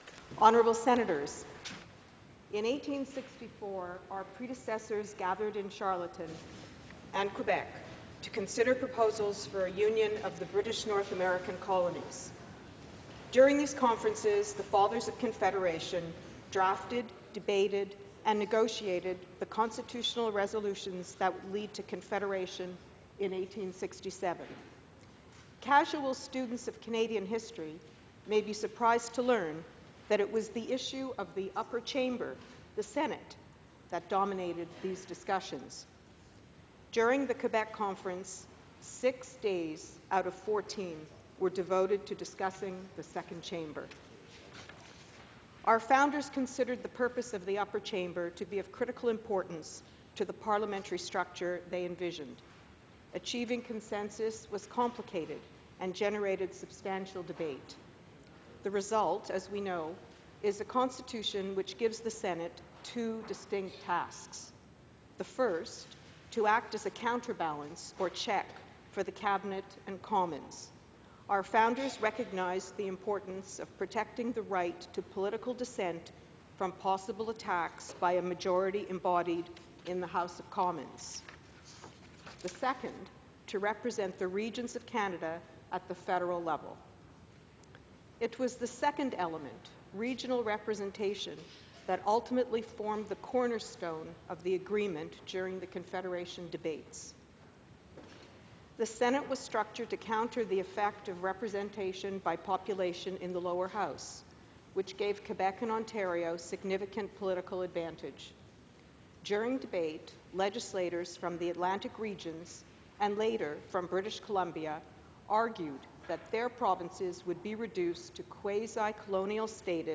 Statements & Speeches